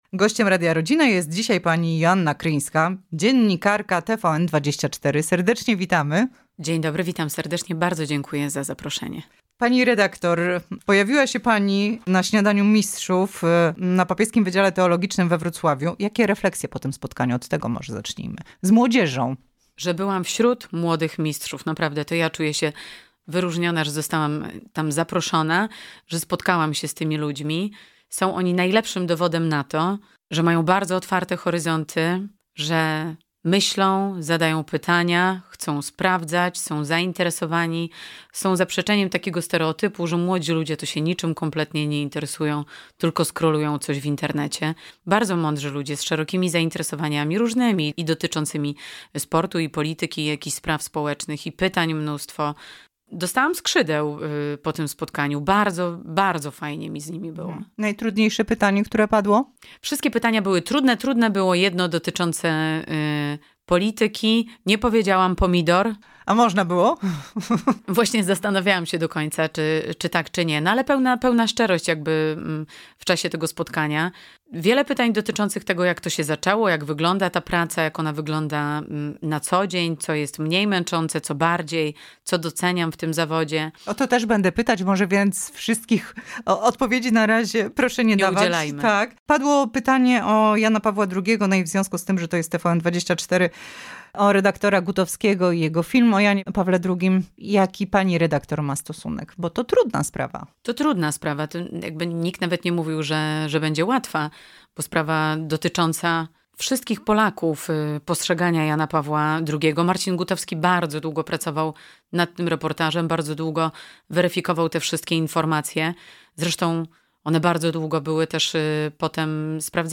Zapraszamy do posłuchania całej rozmowy: